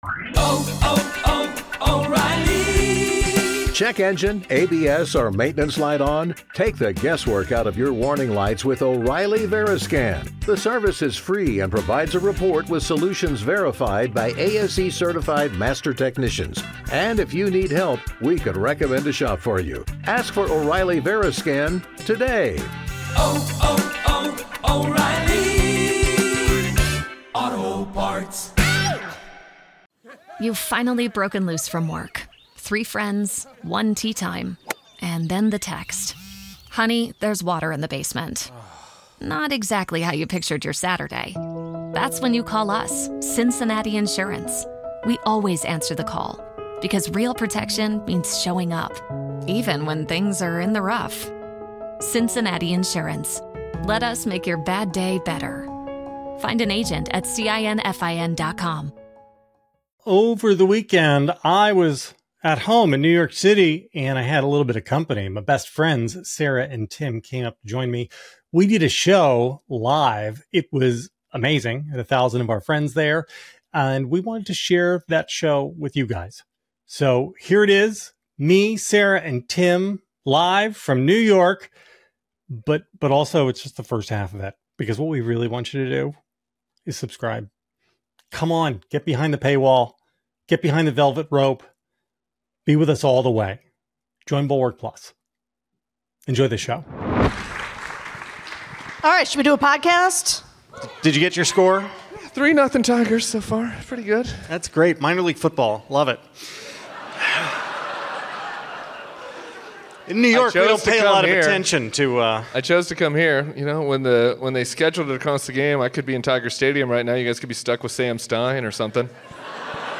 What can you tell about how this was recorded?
Live from New York